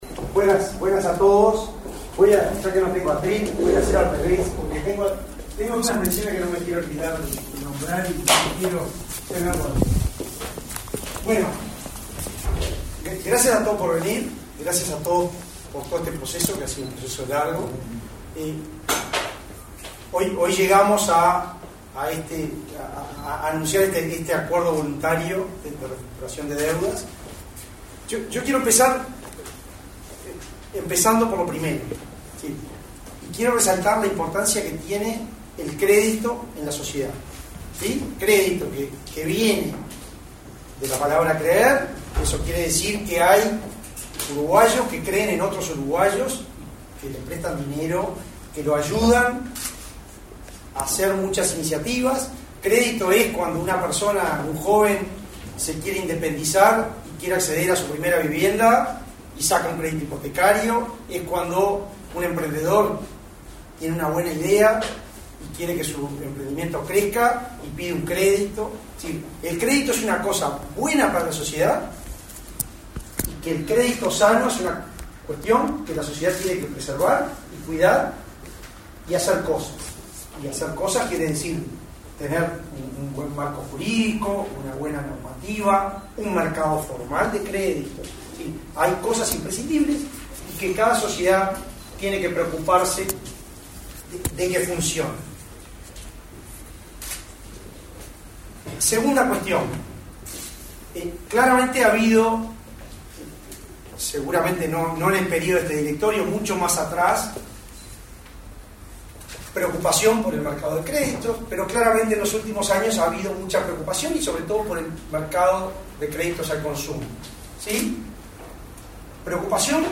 Acto de presentación de programa de restructuración de deudas